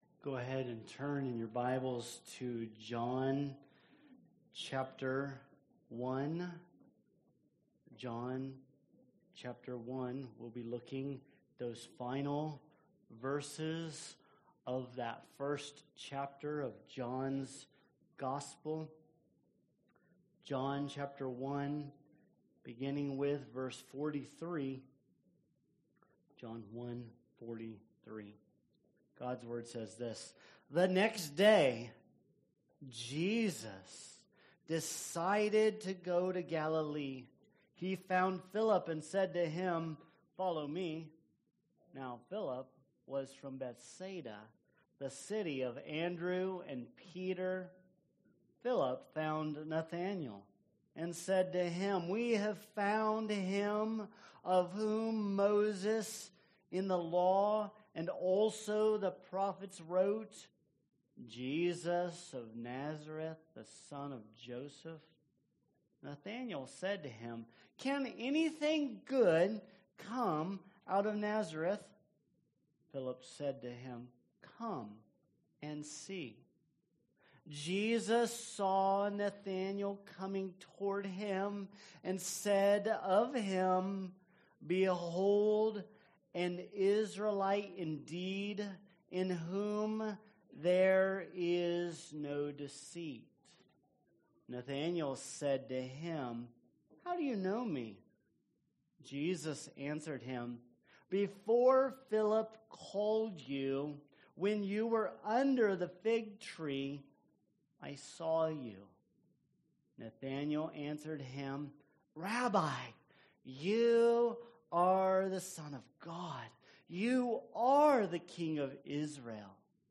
Sermons | Vine Community Church